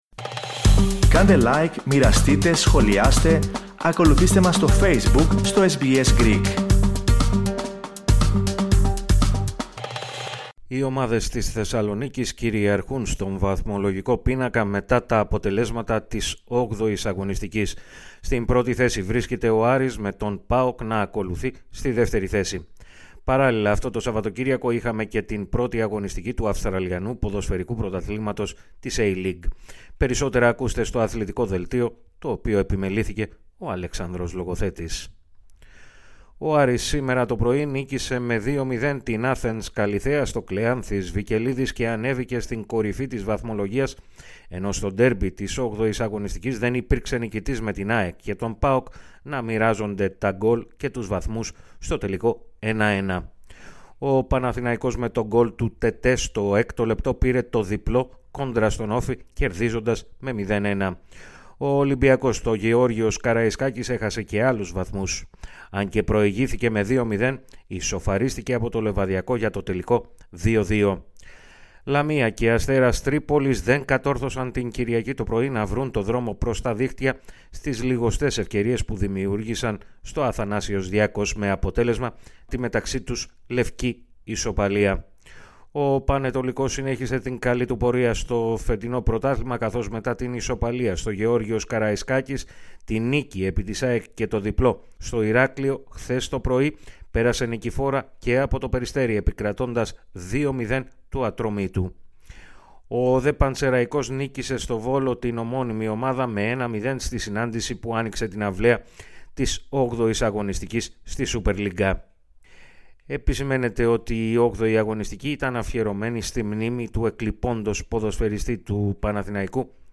Ας ακούσουμε, όμως, πώς σημειώθηκαν τα γκολ στον αγώνα του Άρη απέναντι στην Καλλιθέα, σε περιγραφή της τηλεόρασης Novasports. Ας ακούσουμε και τα γκολ από το ντέρμπι της ΑΕΚ με τον ΠΑΟΚ, στην Opap Arena, σε περιγραφή της τηλεόρασης Cosmote TV.